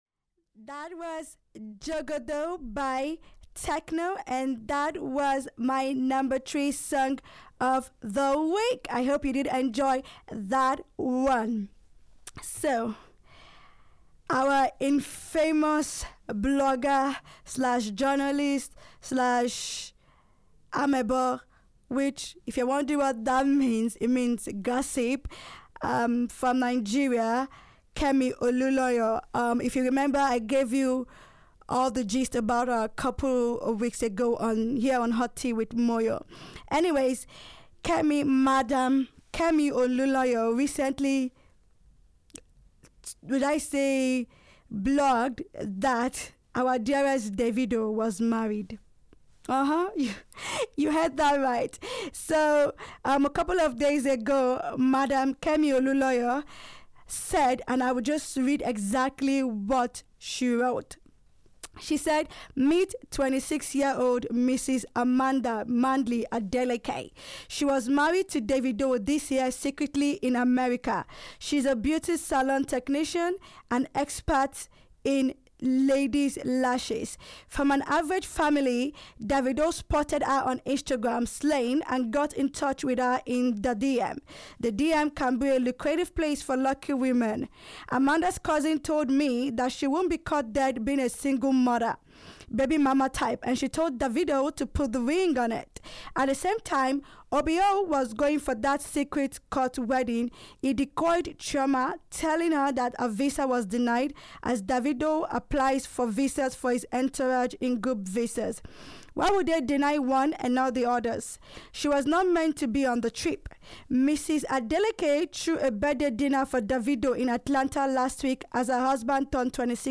International, World-Beat, Hip-Hop, Indie, Pop